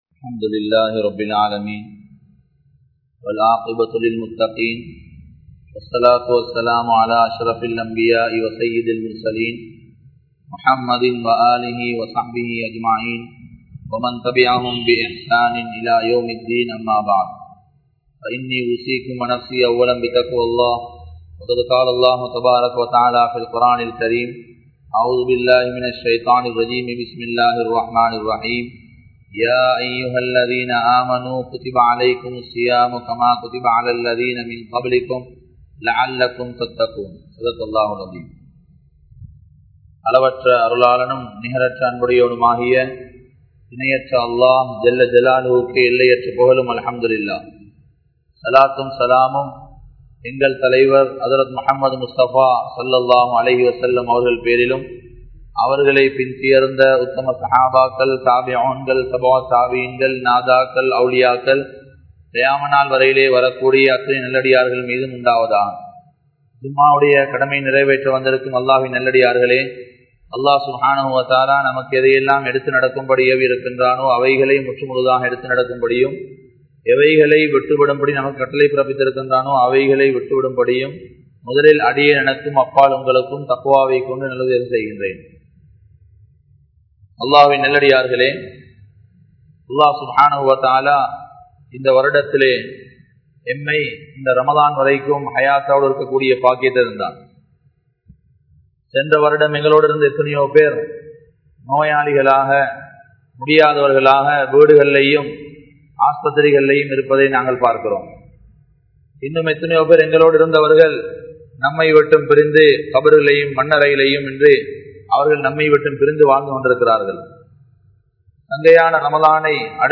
Allah`vai Payanthu Kollugal(அல்லாஹ்வை பயந்து கொள்ளுங்கள்) | Audio Bayans | All Ceylon Muslim Youth Community | Addalaichenai